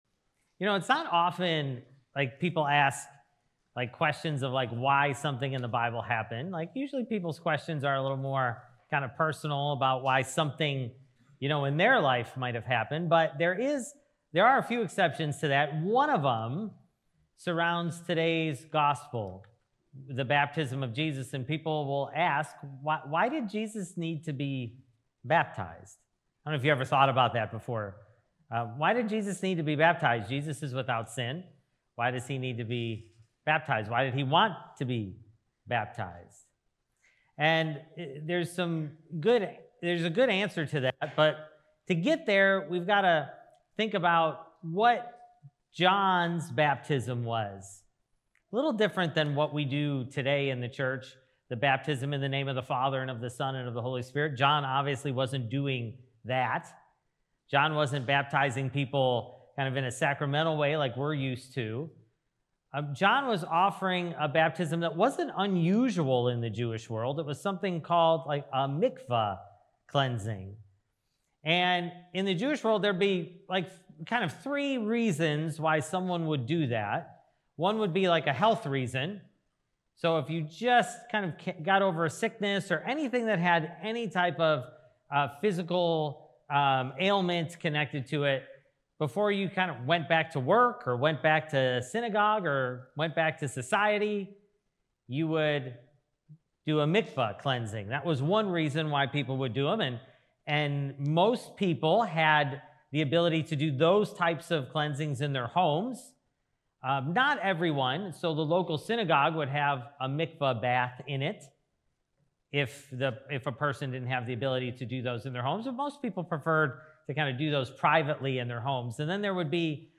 Sermon: A Fresh Start (Matthew 3:13-17)